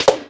Sound effect of "Stomp" in Super Smash Bros. Melee.
SSBM_Stomp.oga